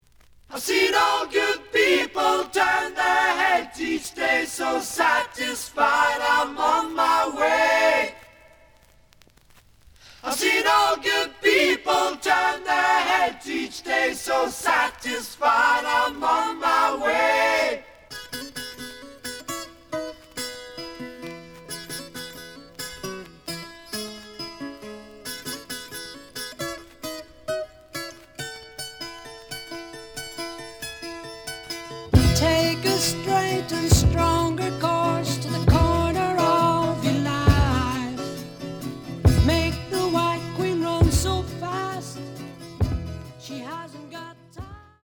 試聴は実際のレコードから録音しています。
●Format: 7 inch
●Genre: Rock / Pop
●Record Grading: EX- (見た目は比較的良好だが、A面若干ノイジー。